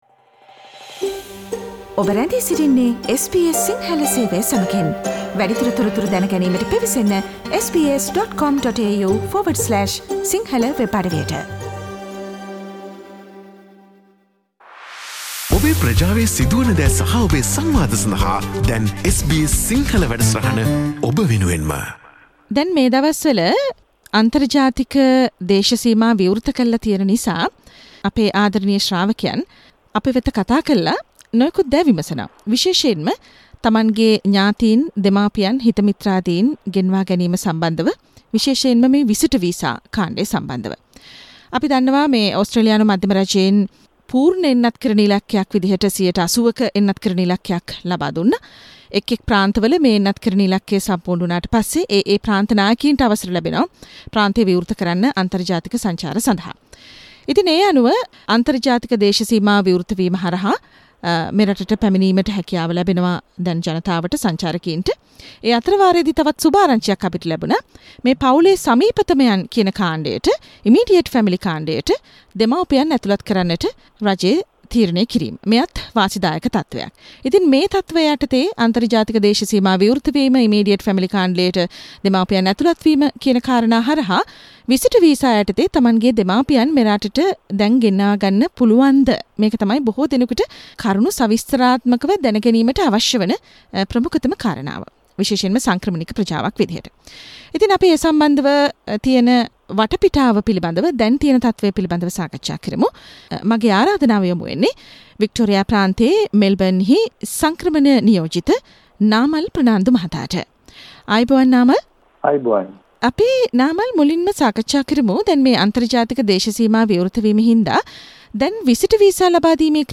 SBS සිංහල ගුවන් විදුලි සේවය